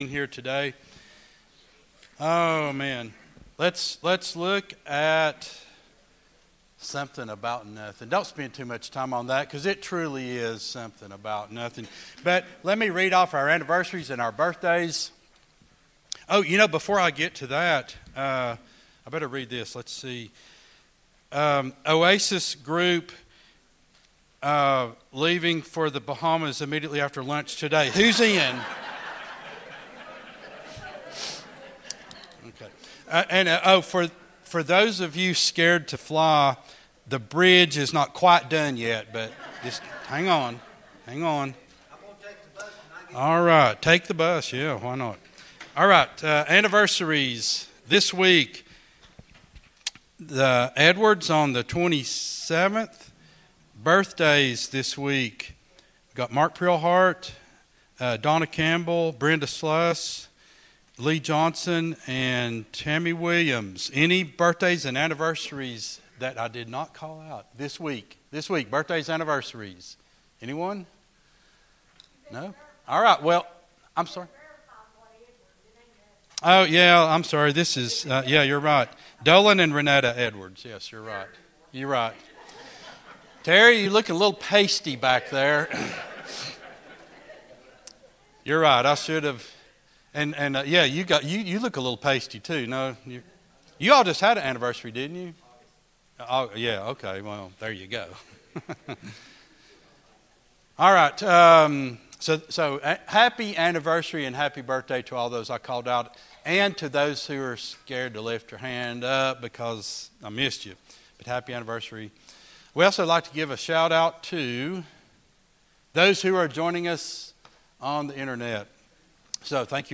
01-21-24 Sunday School Lesson | Buffalo Ridge Baptist Church
at Buffalo Ridge Baptist Church in Gray, Tn.